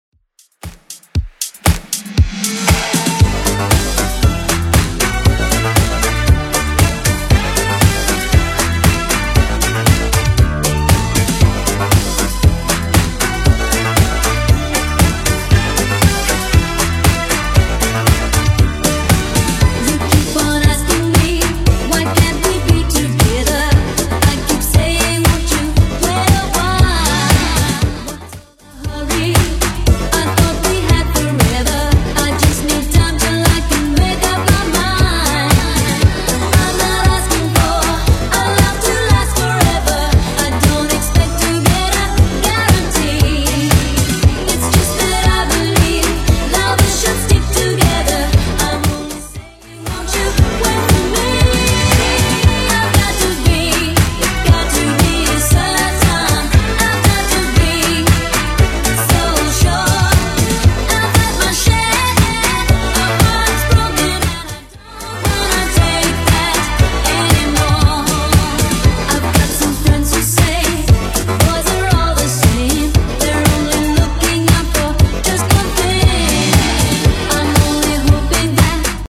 BPM: 117 Time